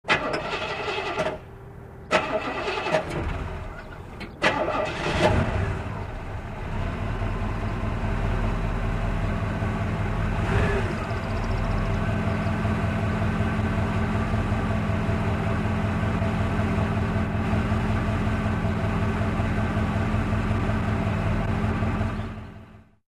Звуки стартера автомобиля
3. Покрутили стартер несколько раз и машина завелась